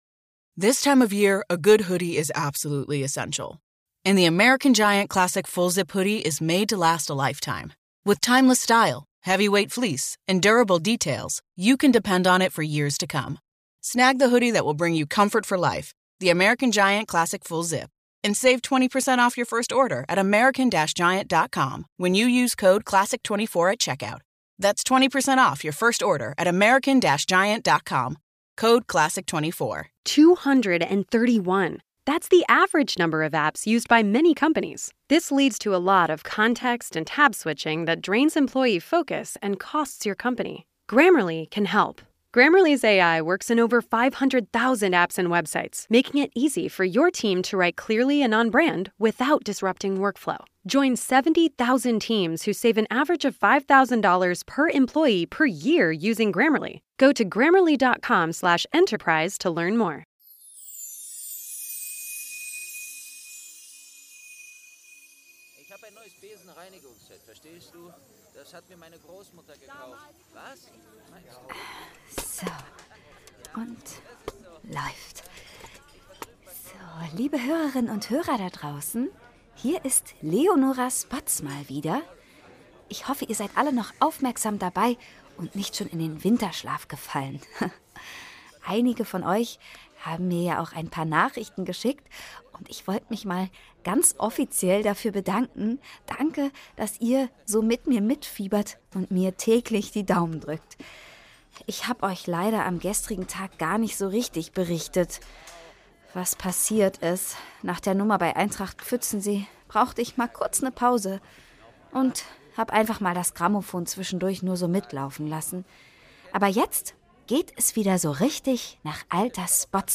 9. Türchen | Professor Dvalishvili - Eberkopf Adventskalender ~ Geschichten aus dem Eberkopf - Ein Harry Potter Hörspiel-Podcast Podcast